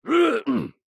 音效